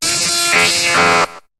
Cri d'Élektek dans Pokémon HOME.